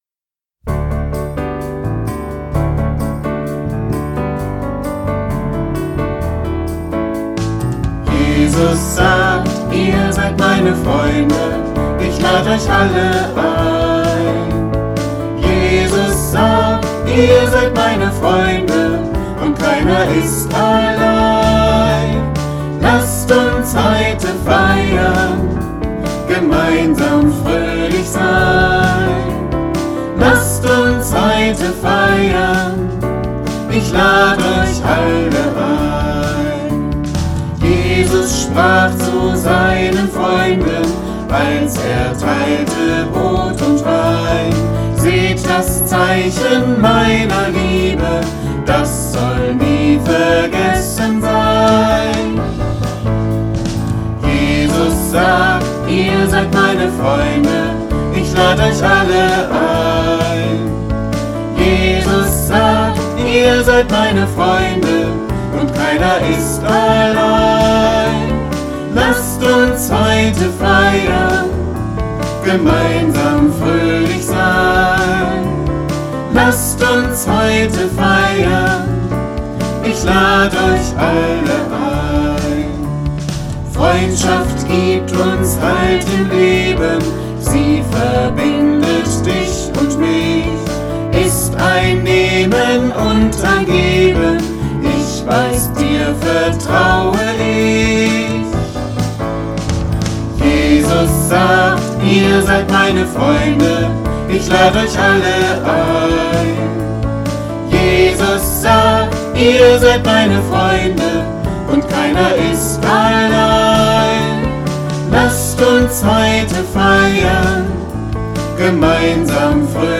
Originalversion